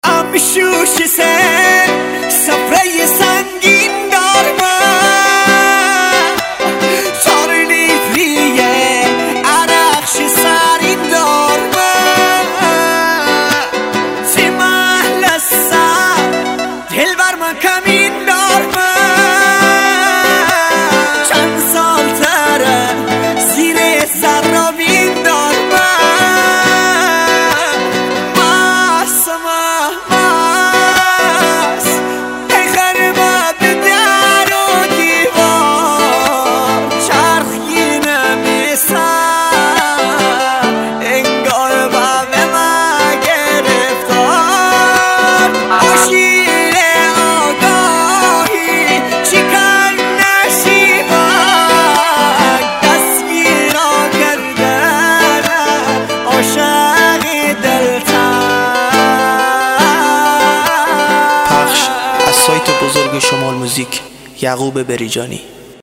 ریمیکس گیتاری